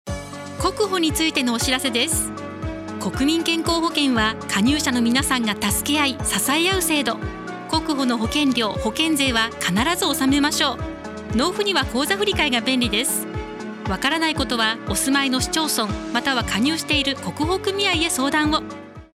国民健康保険料（税）の納付促進 ラジオCM ・CMコメント 国保についてのお知らせです。 国民健康保険は、加入者の皆さんが助け合い、支え合う制度。